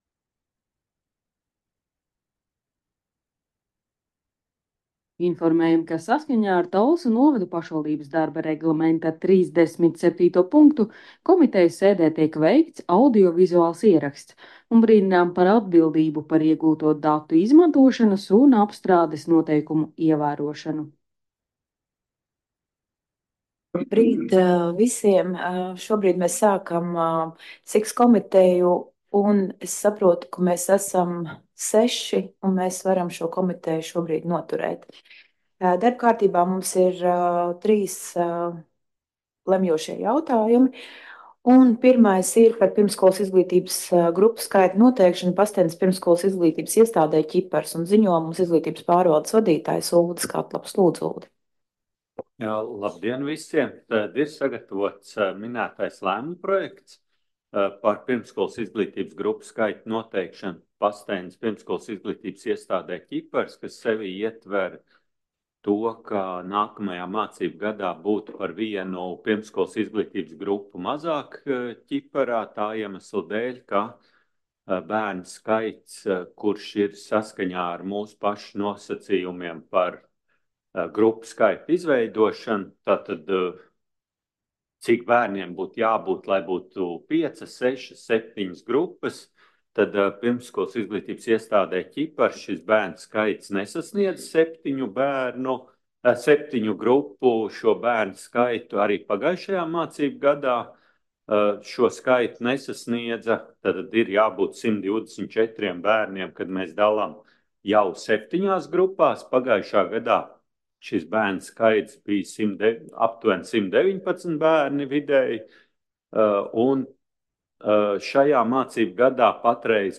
Sociālo, izglītības, kultūras un sporta jautājumu komitejas sēde Nr. 7